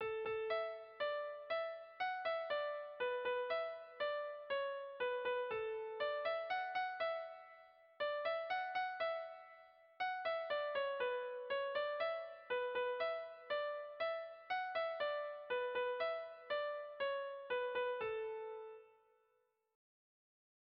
Gabonetakoa
Kopla handiaren moldekoa
A-B-C-A